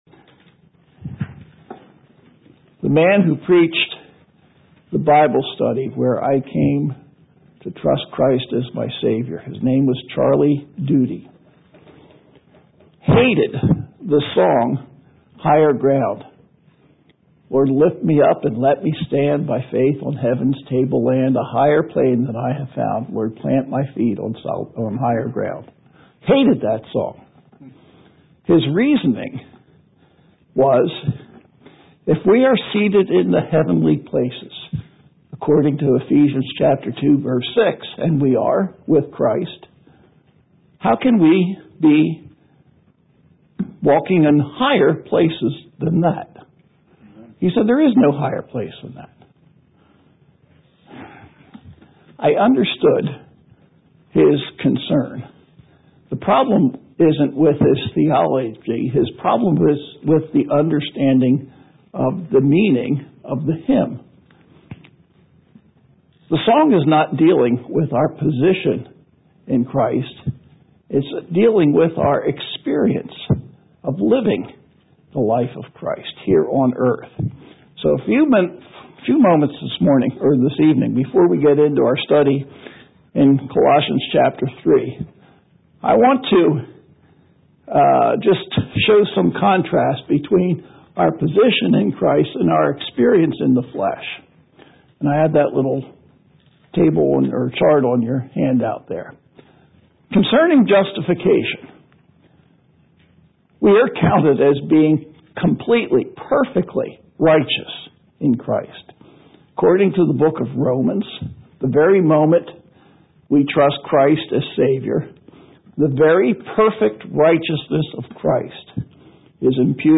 Worship Messages